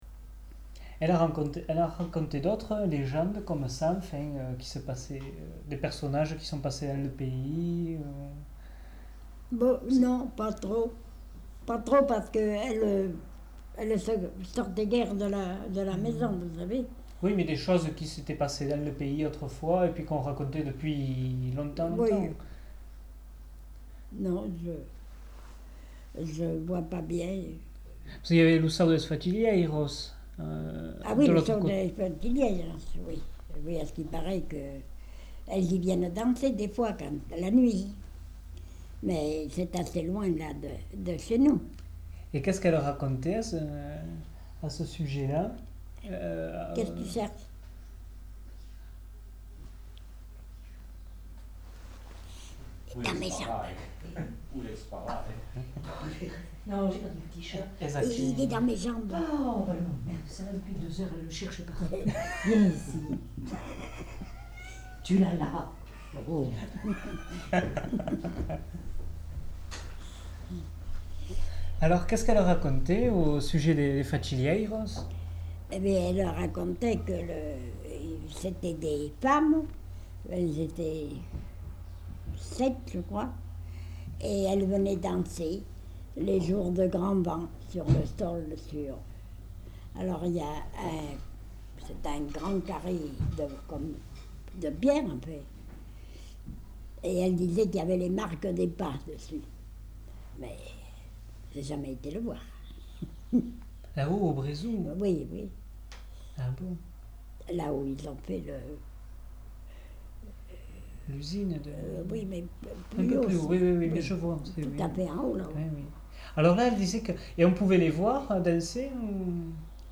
Lieu : Tournay
Genre : conte-légende-récit
Effectif : 1
Type de voix : voix de femme
Production du son : parlé
Classification : récit légendaire